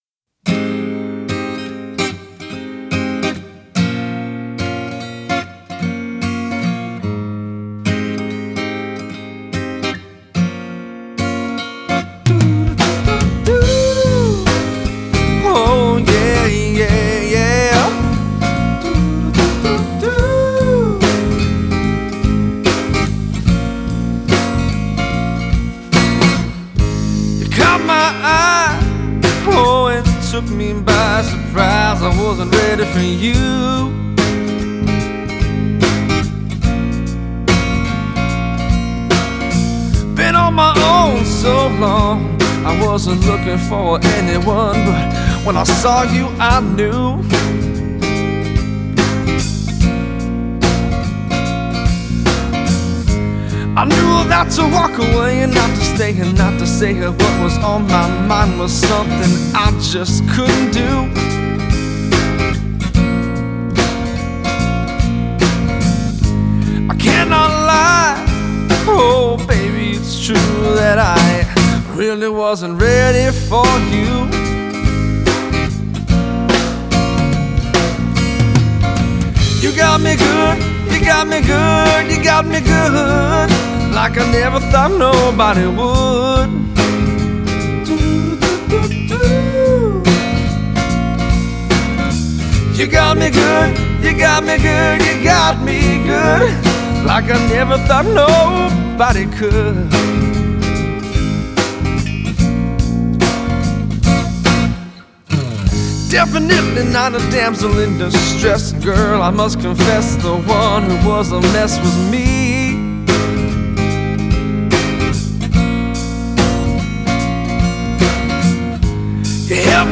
slide guitar